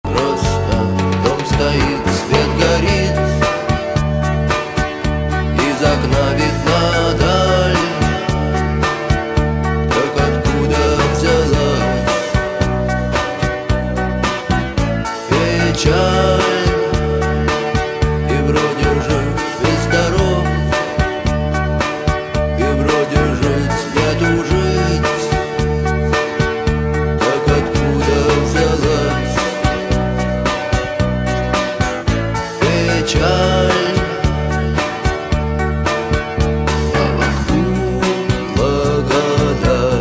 • Качество: 320, Stereo
мужской вокал
грустные
русский рок